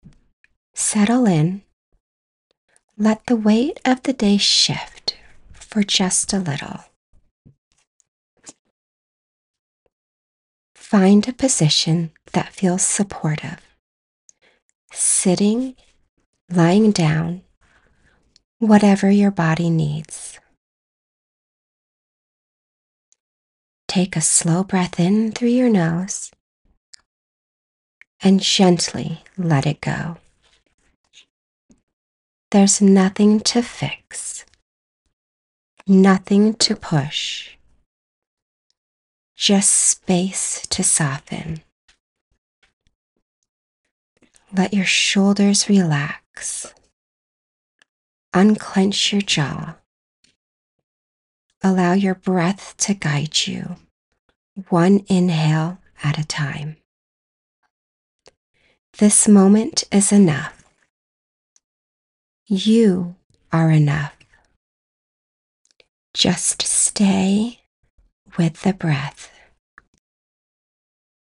Guided Meditation - Grounded, Calm, Natural.mp3
Middle Aged